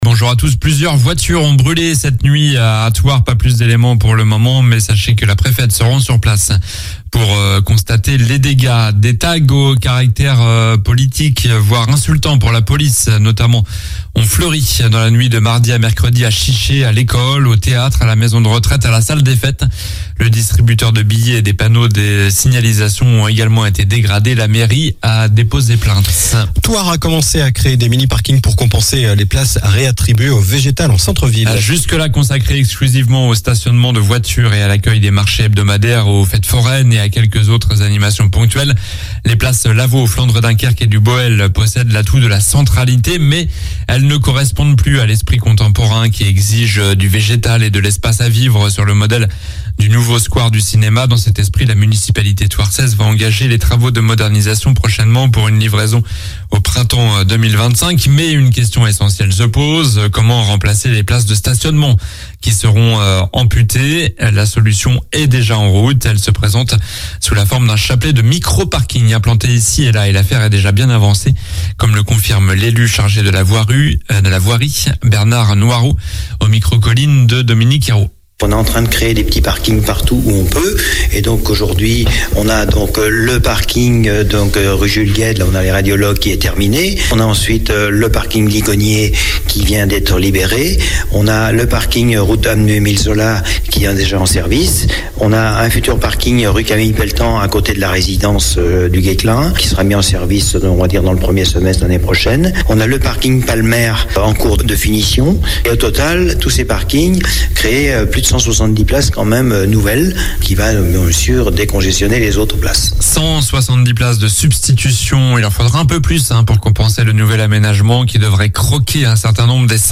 Journal du samedi 29 juillet (midi)